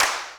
VEC3 Claps 015.wav